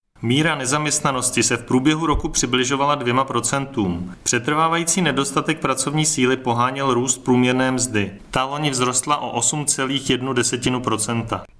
Vyjádření Marka Rojíčka, předsedy ČSÚ, soubor ve formátu MP3, 455.14 kB